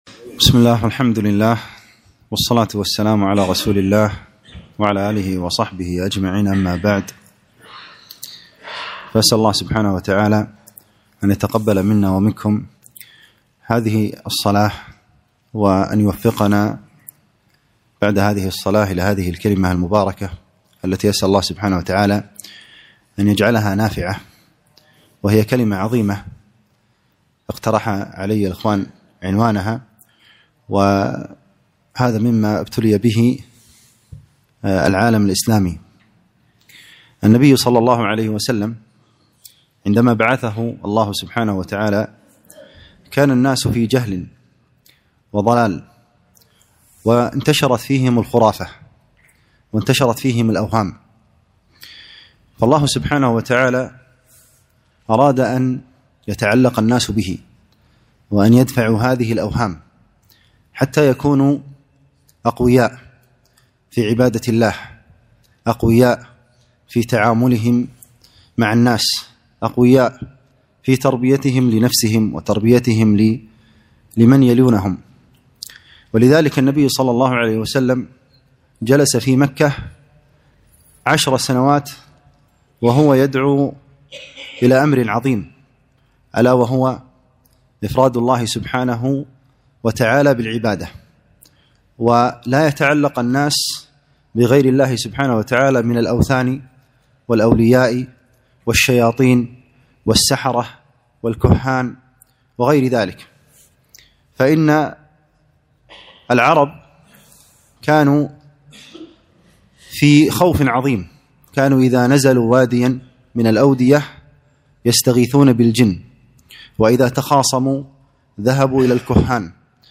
كلمة - السحر والشعوذة وخطورتھما على العقيدة